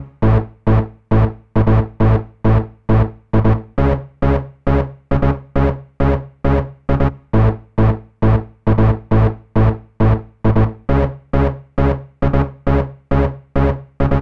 TI CK7 135  Detune Saw 2.wav